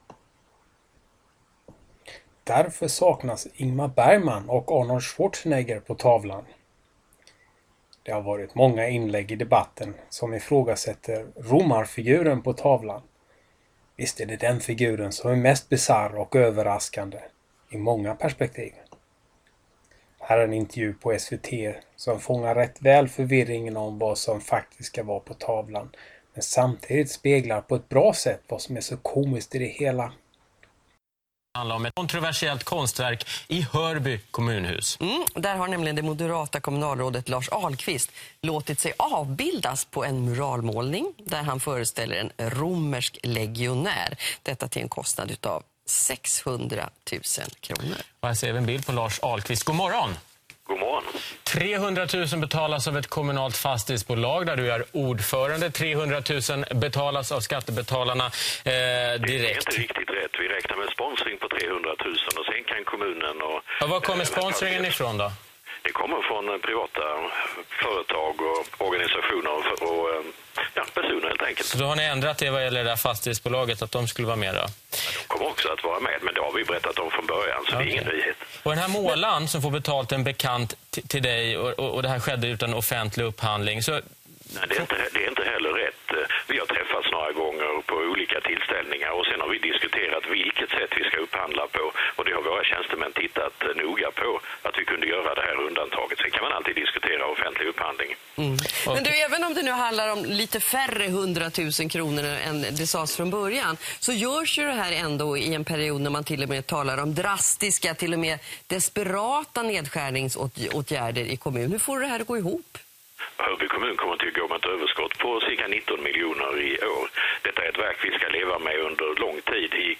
Här en intervju på SVT som fångar rätt väl förvirringen om vad som faktiskt skall vara på tavlan, men samtidigt speglar på ett bra sätt vad som är så komiskt i det hela: